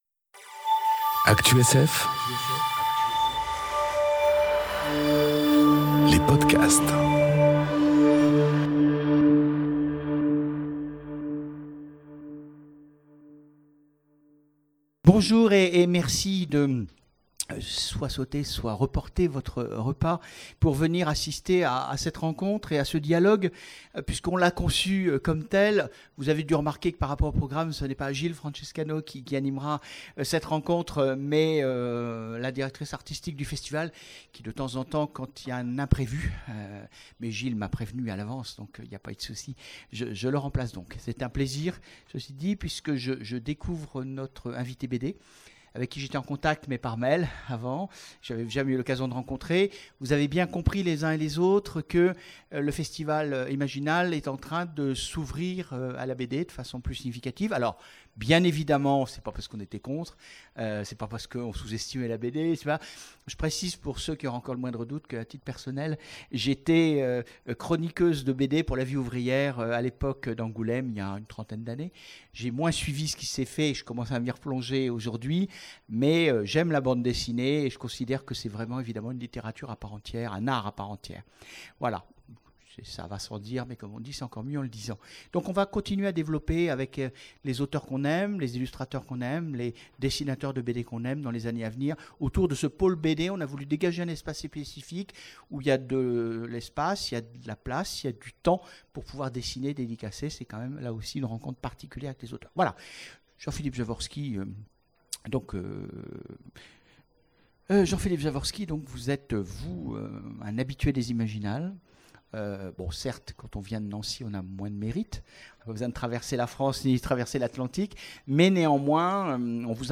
Conférence Imaginer un héros de fantasy... Et l'adapter en BD ? enregistrée aux Imaginales 2018